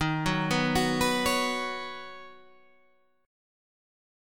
Ebm7#5 Chord